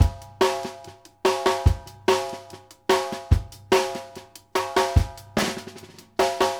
Index of /90_sSampleCDs/Sampleheads - New York City Drumworks VOL-1/Partition F/SP REGGAE 72